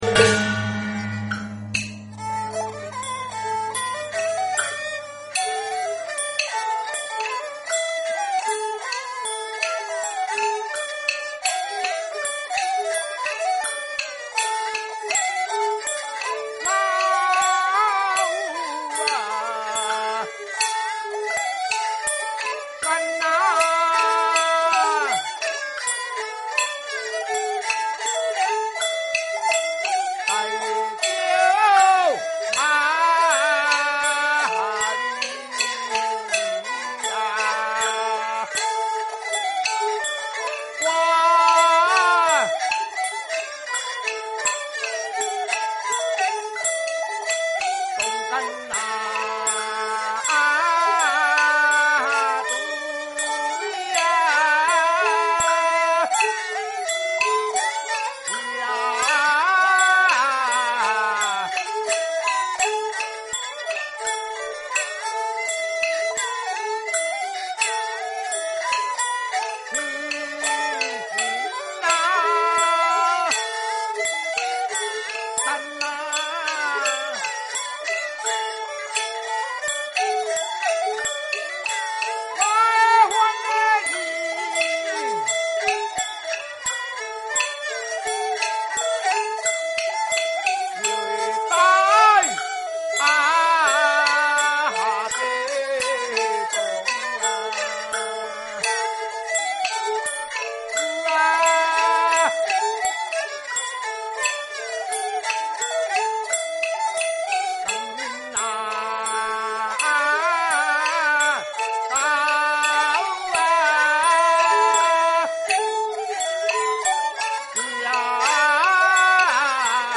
架造【平板】 北管古路戲曲 梨春園早期錄音資料計畫